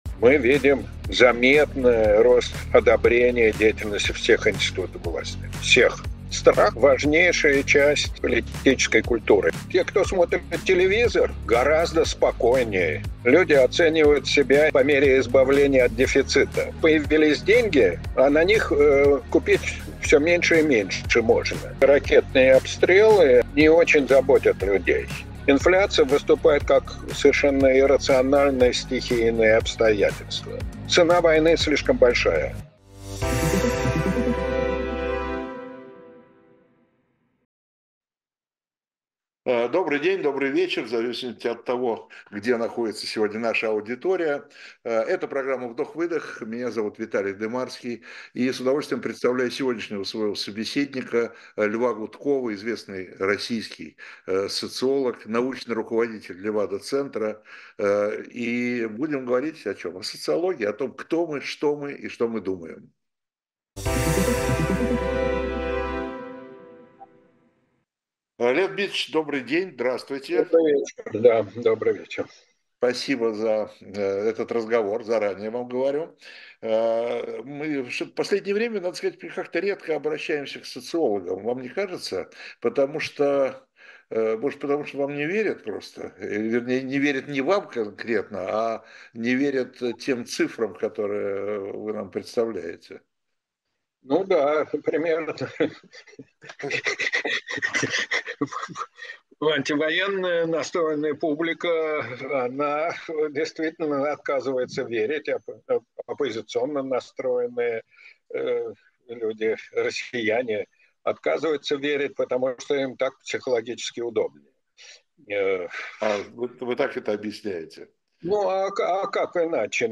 Эфир ведёт Виталий Дымарский